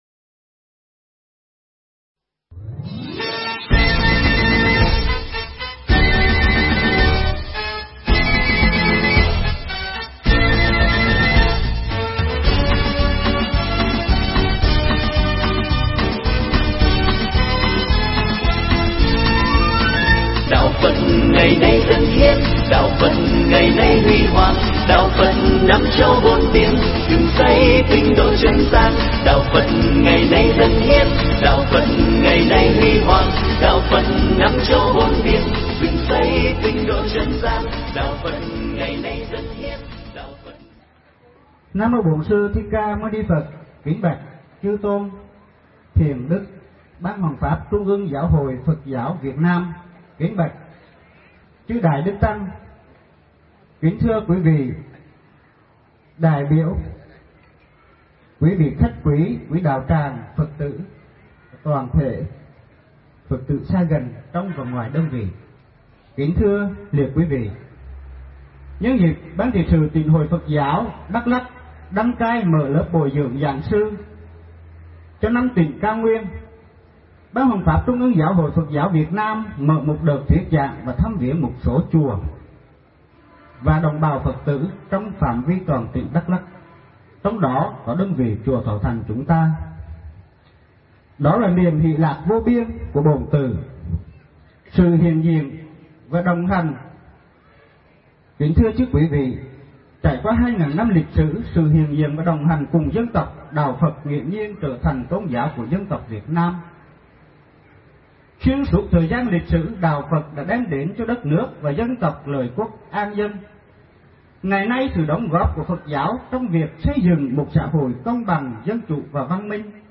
Nghe mp3 Pháp thoại Chăm Sóc Hạnh Phúc – phần 1/2 được thầy Thích Nhật Từ thuyết giảng tại Chùa Thọ Thành, tỉnh DakLak, ngày 19 tháng 12 năm 2008